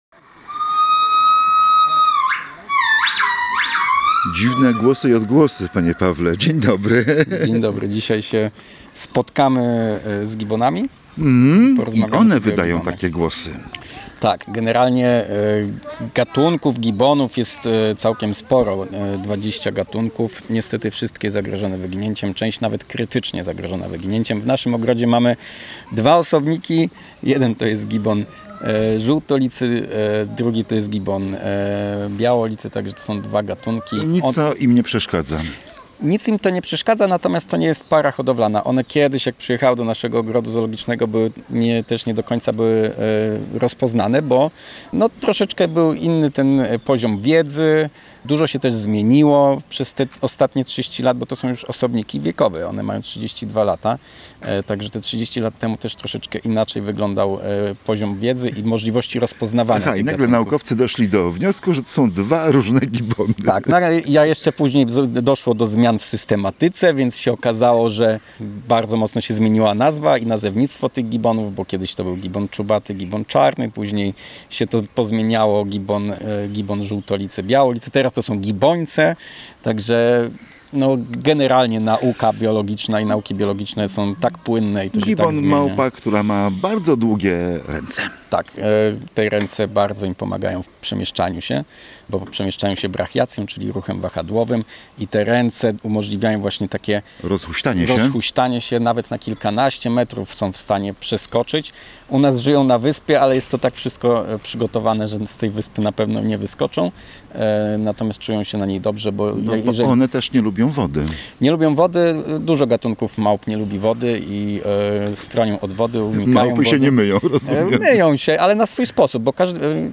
Posłuchaj rozmowach o gibbonach: